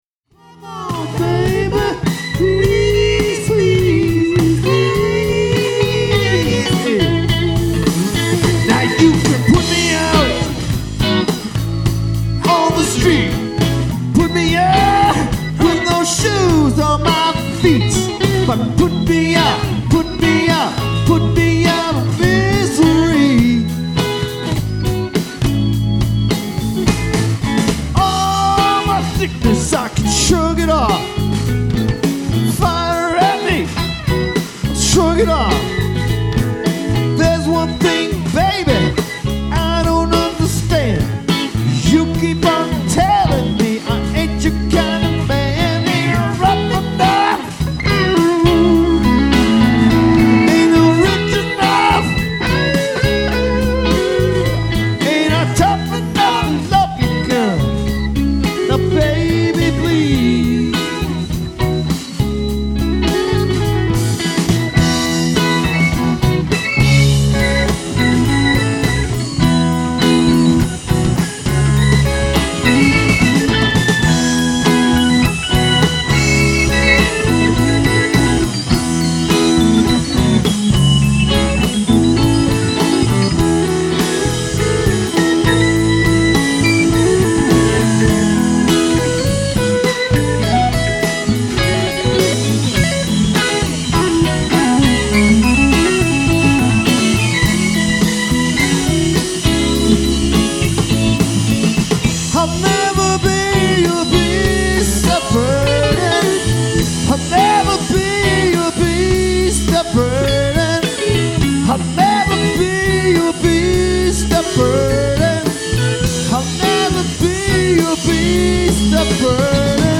Lead Guitar, Vocals